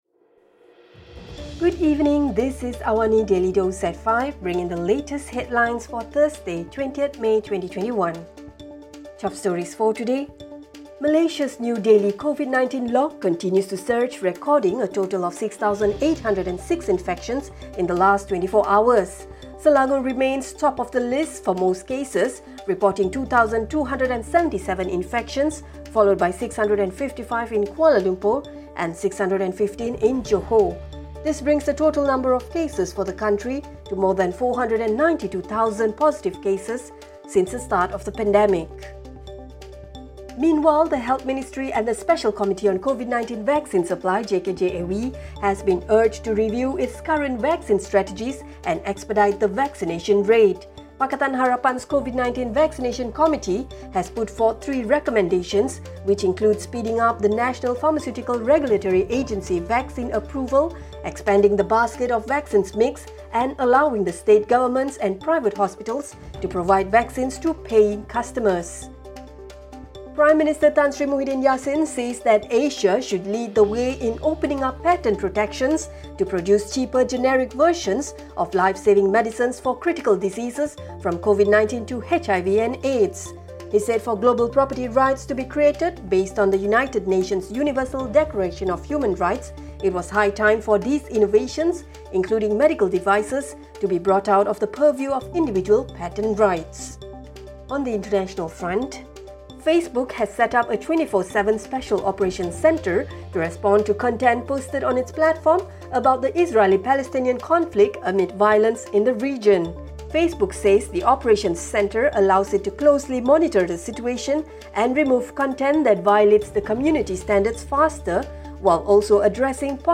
Listen to the top stories of the day, reporting from Astro AWANI newsroom — all in 3-minutes.